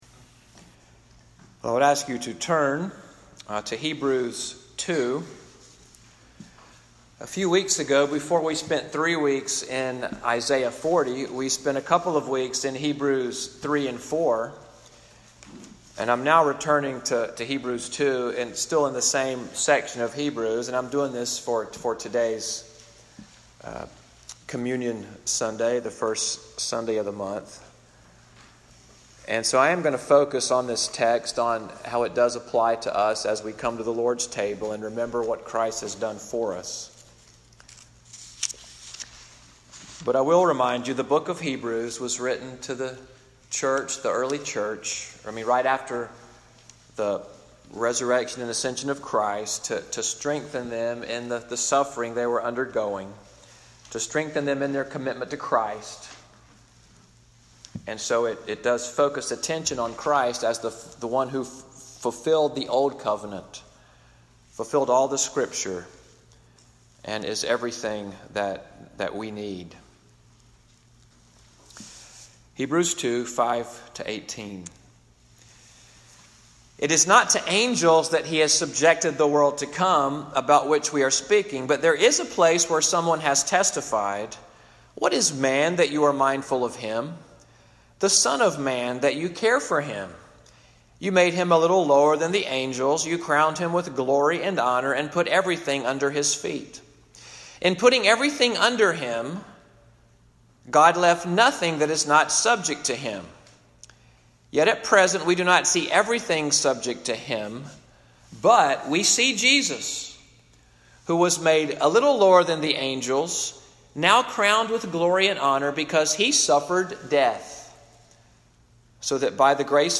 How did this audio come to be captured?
Sermon audio from the morning worship of Little Sandy Ridge Presbyterian Church in Fort Deposit, Alabama.